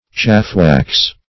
Search Result for " chaffwax" : The Collaborative International Dictionary of English v.0.48: Chafewax \Chafe"wax`\, or Chaffwax \Chaff"wax`\, n. (Eng. Law) Formerly a chancery officer who fitted wax for sealing writs and other documents.
chaffwax.mp3